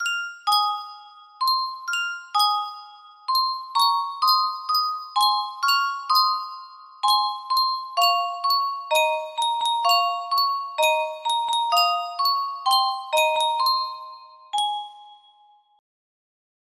Yunsheng Music Box - It's Raining, It's Pouring 4882 music box melody
Full range 60